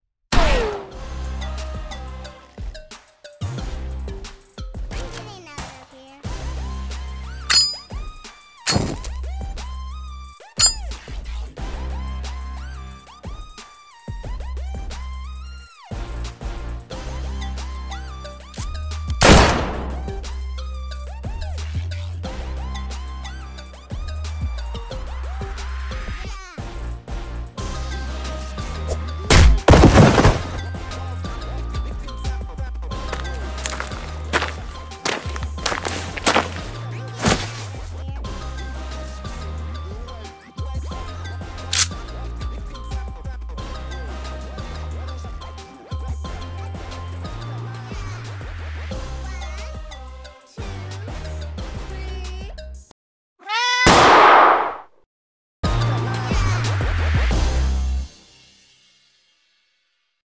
相死相愛とか 【銃 掛け合い 2人声劇】